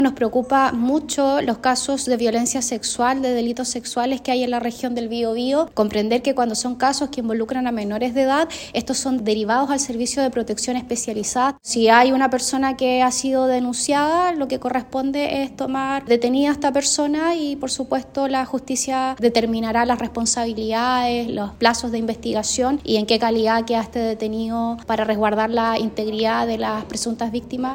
Por su parte, la Seremi de la Mujer y Equidad de Género del Biobío, Camila Contreras, quien hizo un llamado a denunciar para que las personas involucradas enfrenten a la justicia.